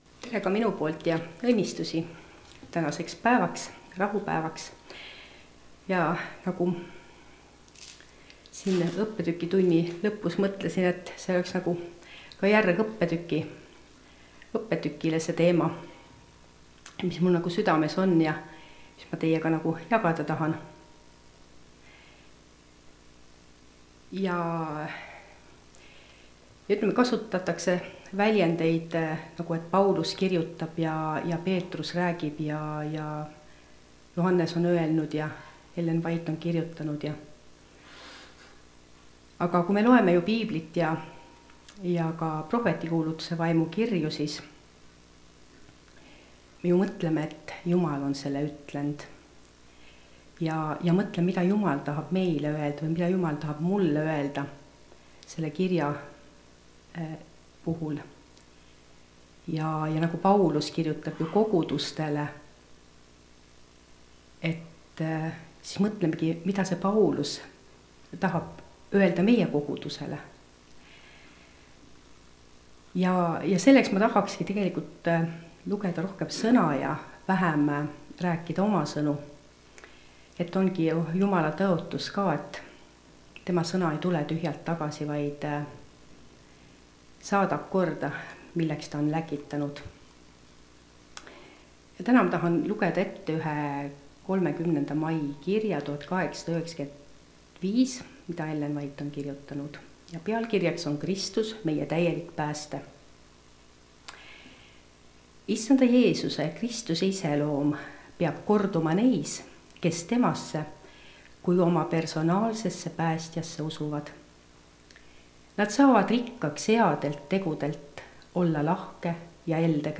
meie isiklik päästja (Rakveres)
Jutlused